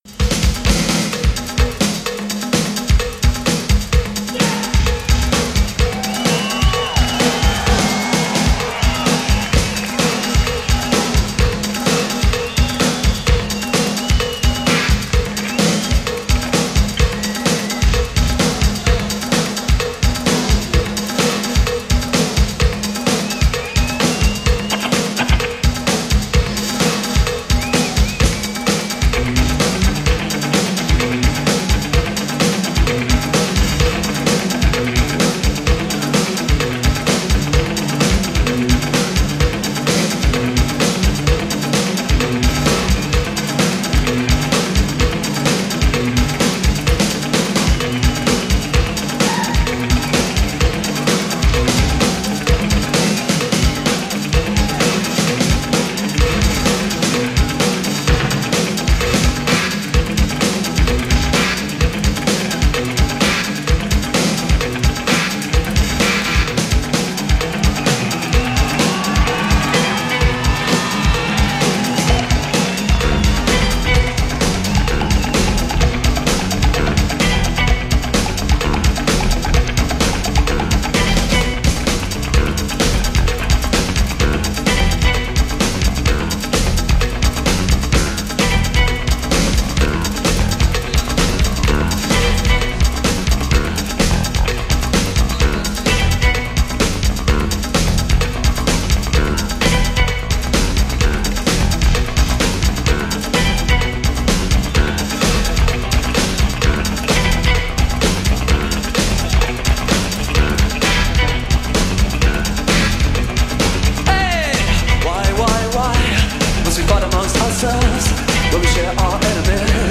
Frantic 80s at Glastonbury Festival.
unpredictable, frenetic and high energy as a live band
Raw, unpredictable, high-octane